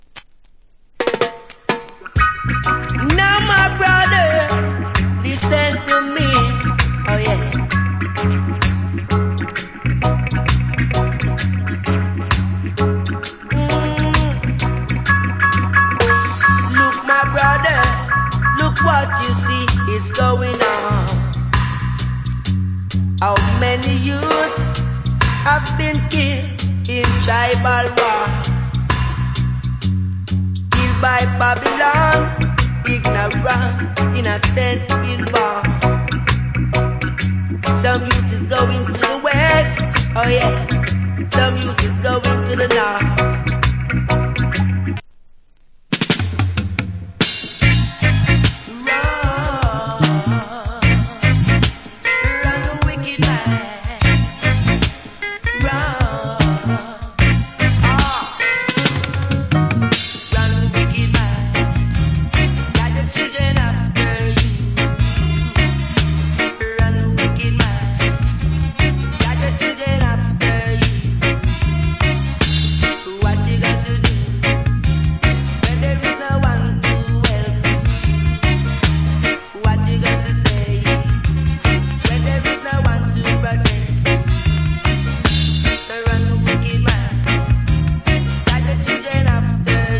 Cool Reggae Vocal + Dub.